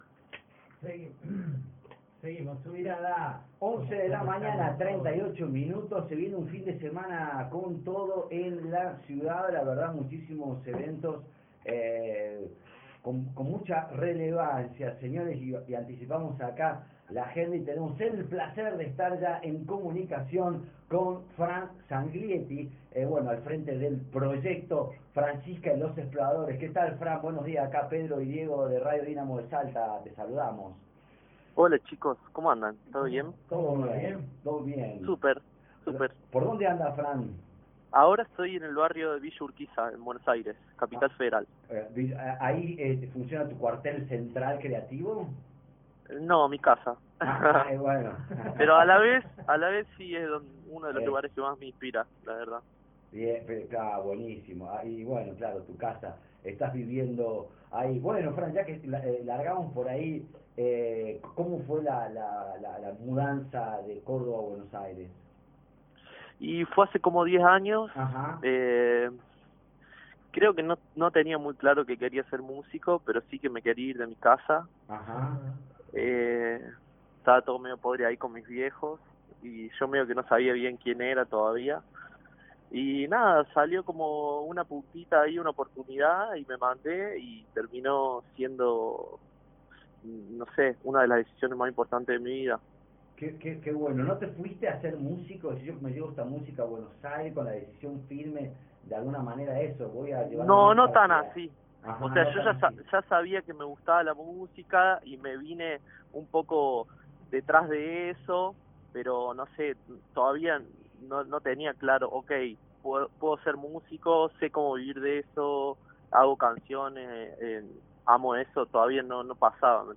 Escucha la nota completa: